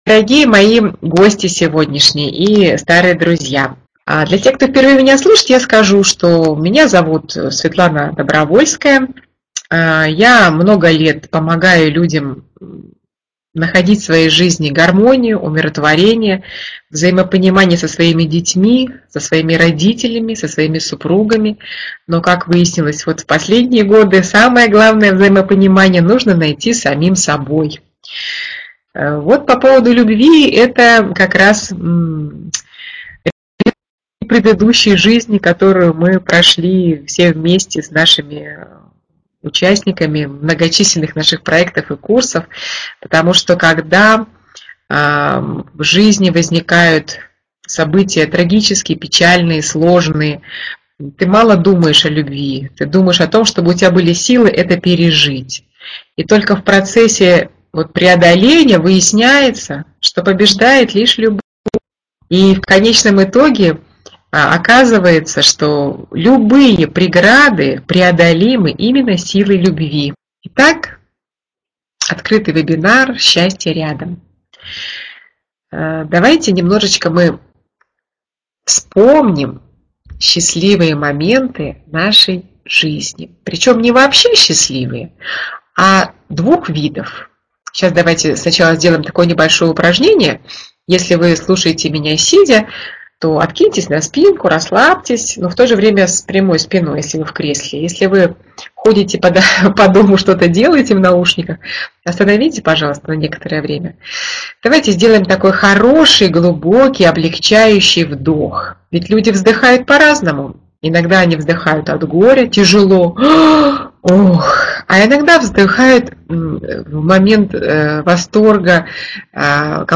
obreteniesili.ru_webinar_schastie_ryadom.mp3